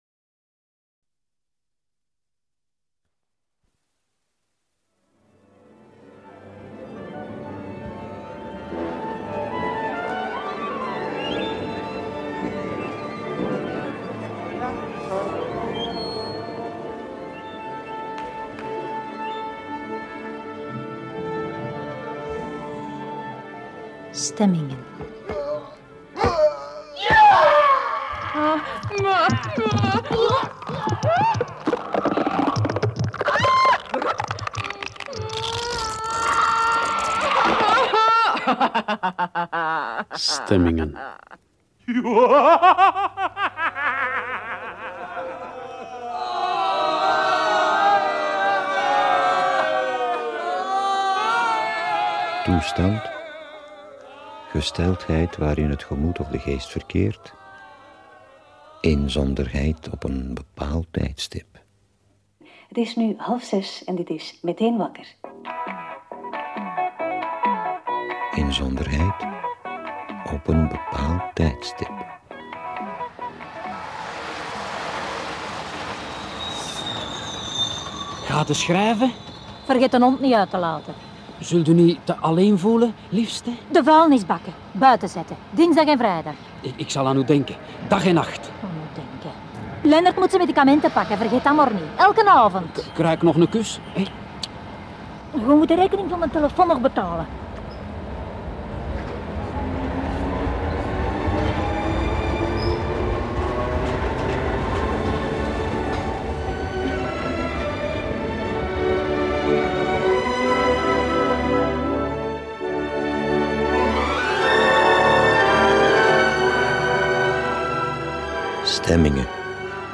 In deze radiodocumentaire wisselen oorspronkelijke getuigenissen af met gedramatisseerde geschreven monologen-dialogen. Klankdecors en muziek zorgen voor omgeving en verbinding. Het begrip ‘stemmingen’ wordt letterlijk en figuurlijk benaderd. Vanuit de stemming in een orkest naar stemmingen van het gemoed in diverse situaties.
01-stemmingen-radiodocumentaire-prix-italia-1.mp3